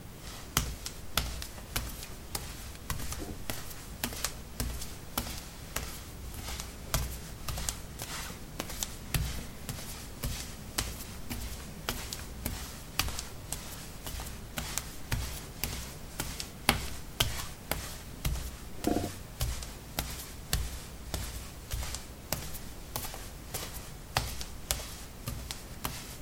脚踏实地的陶瓷砖 " 陶瓷 01b barefeet shuffle tap
描述：在瓷砖上拖地：赤脚。在房子的浴室里用ZOOM H2记录，用Audacity标准化。